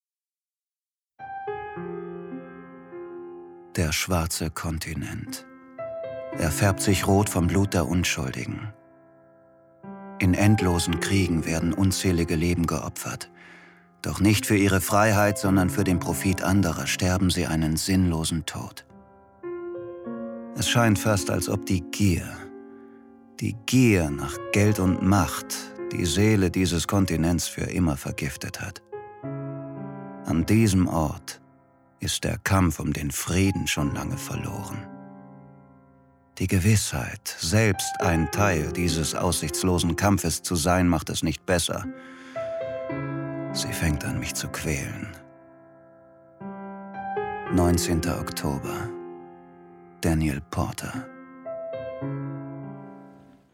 Hörbücher